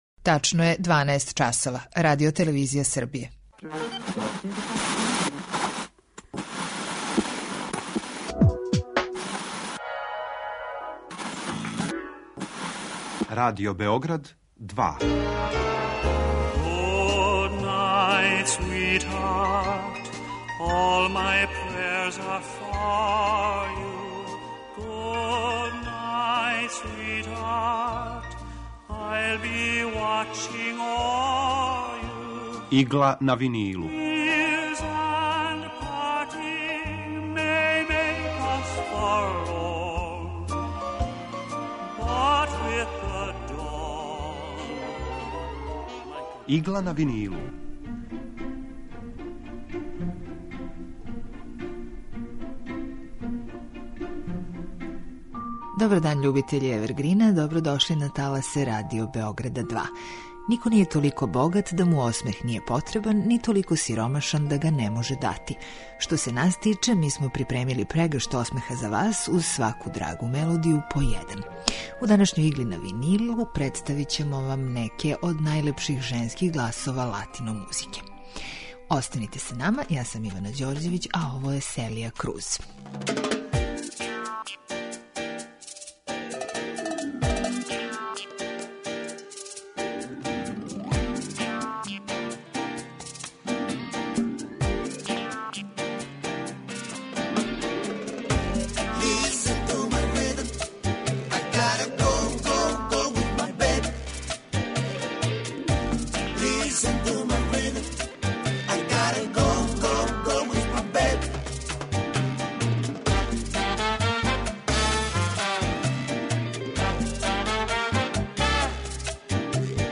Емисија евергрин музике